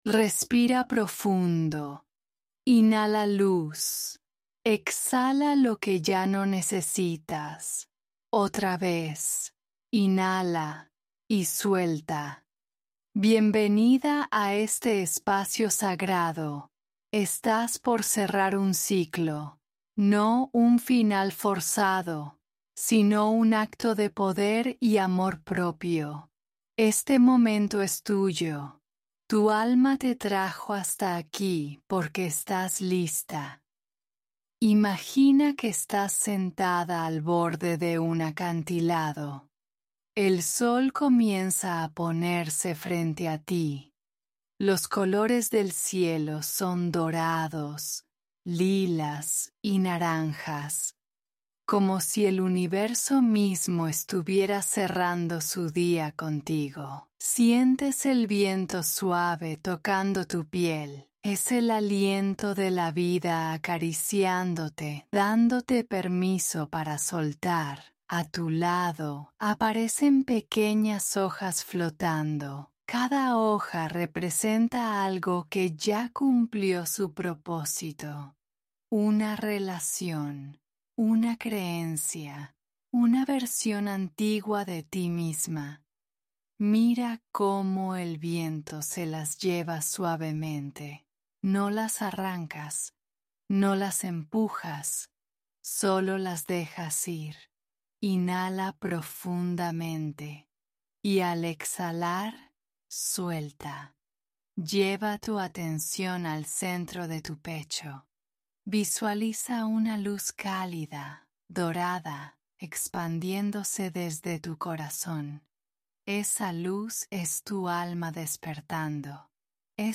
Meditacion 🧘🏽‍♀